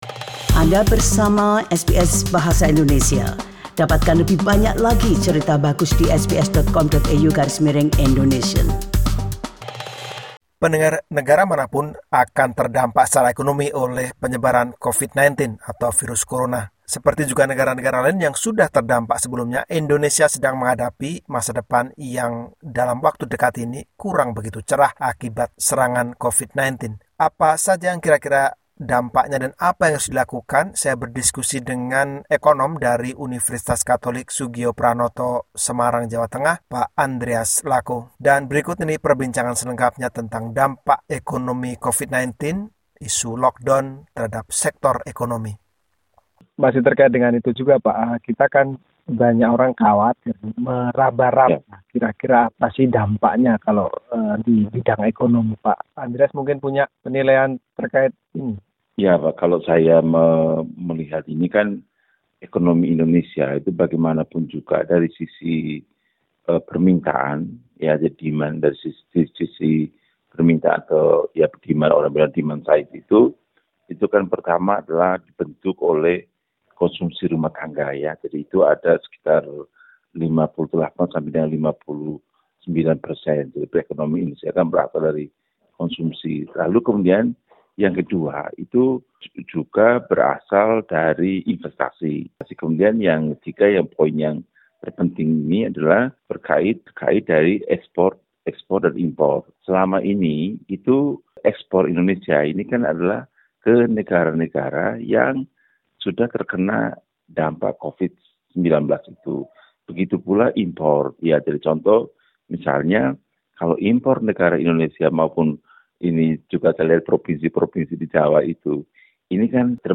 Listen the full discussion with economist from Soegijapranata Catholic University